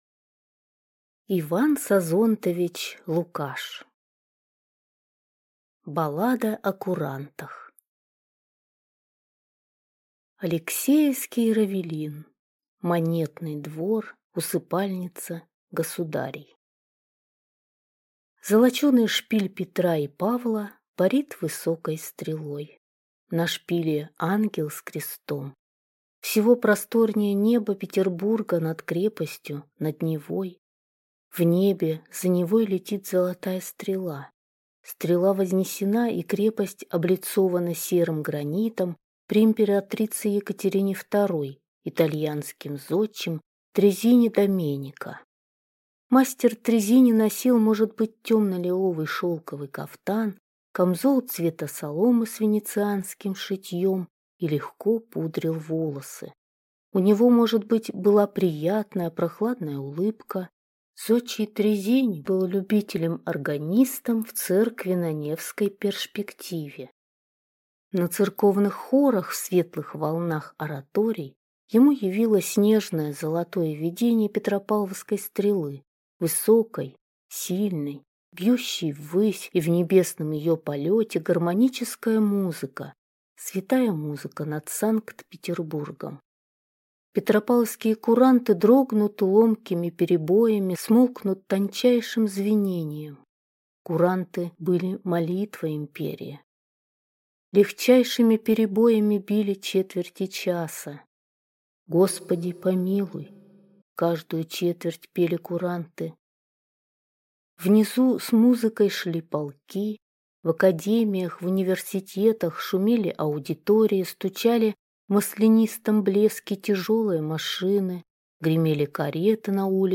Аудиокнига Баллада о курантах | Библиотека аудиокниг